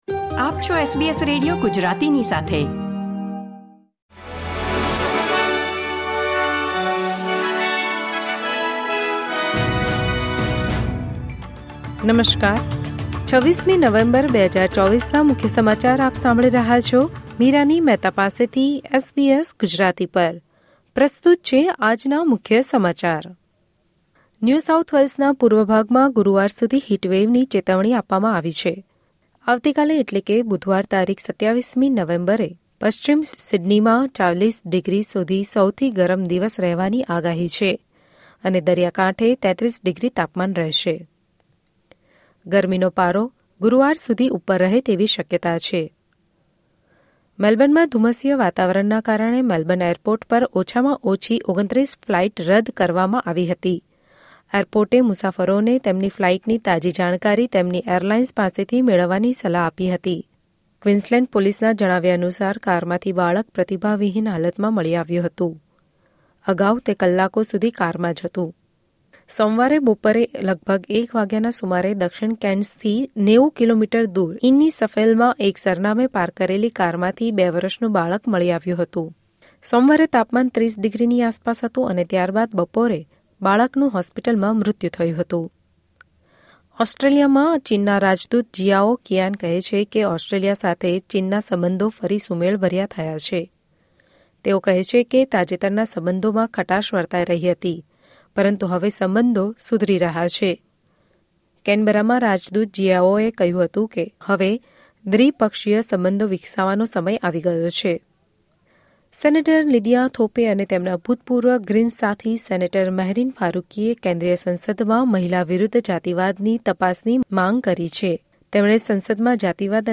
SBS Gujarati News Bulletin 26 November 2024